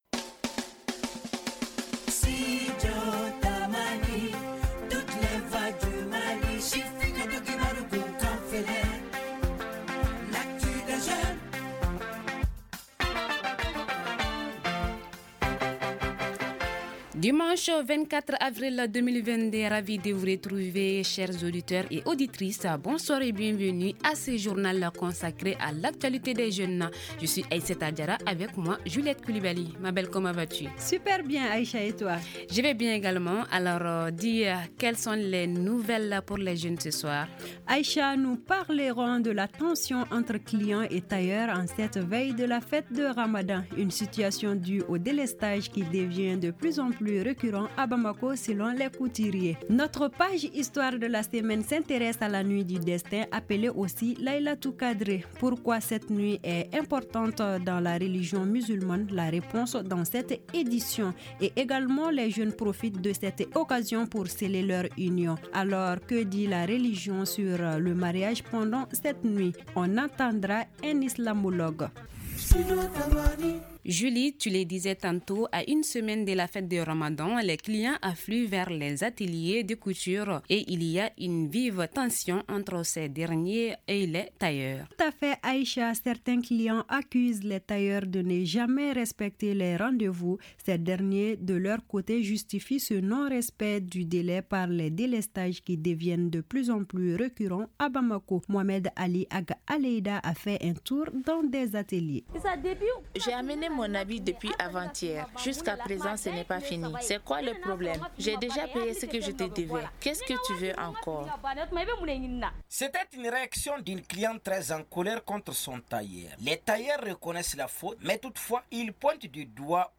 Que dit la religion musulmane sur le mariage pendant la nuit du destin ? Un islamologue se prononce sur la question.